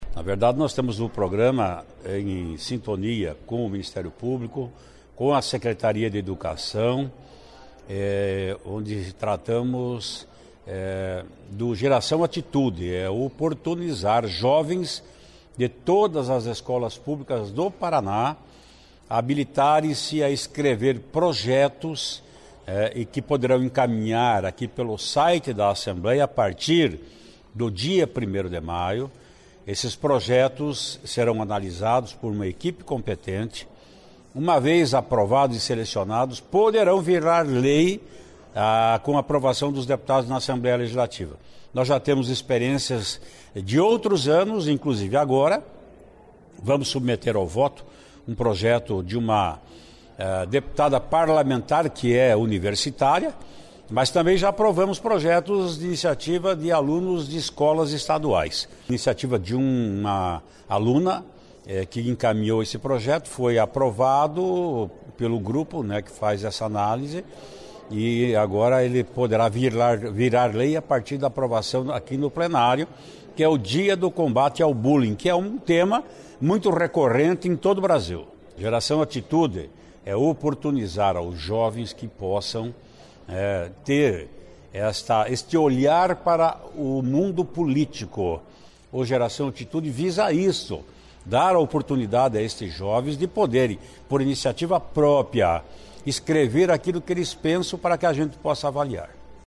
Ouça a entrevista com o presidente Ademar Traiano (PSDB), onde ele fala sobre as novidades no geração Atitude deste ano.